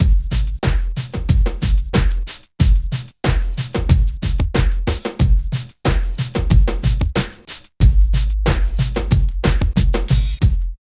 100 LOOP  -R.wav